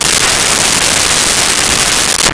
Car Attempt To Start With Dead Battery
Car Attempt to Start with Dead Battery.wav